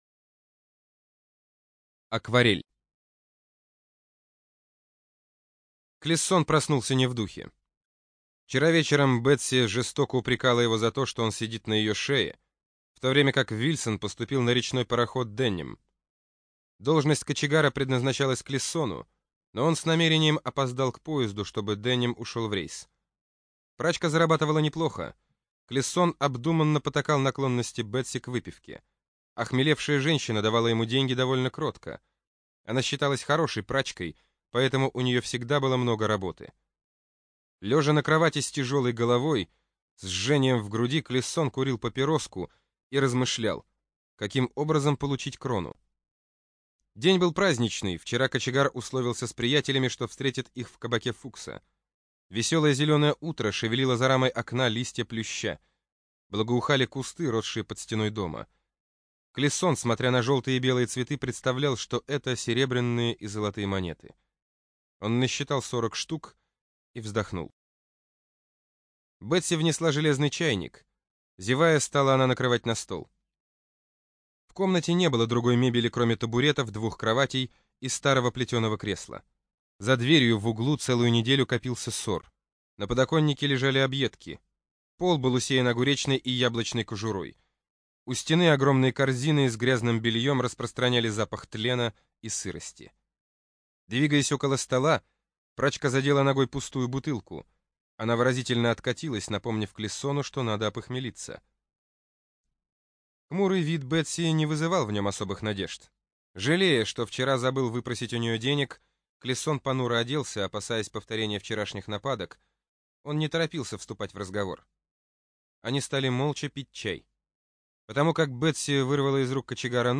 Акварель - аудио рассказ Александра Грина - слушать онлайн